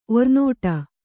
♪ ornōṭa